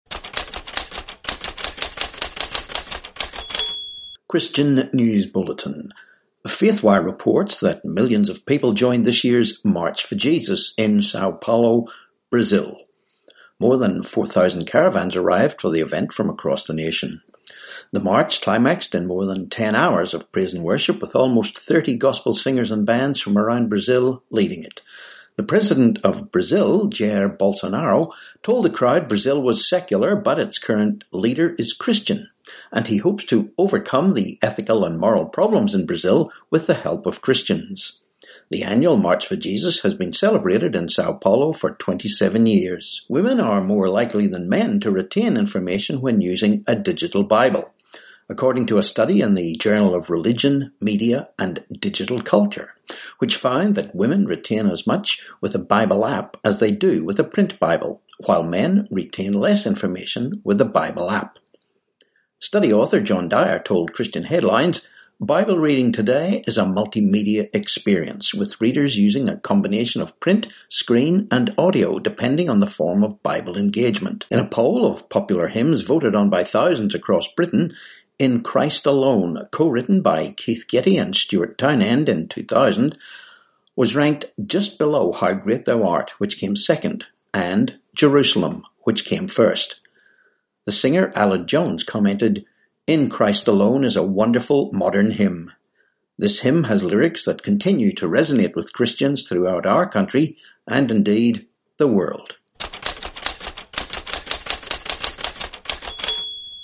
13Oct19 Christian News Bulletin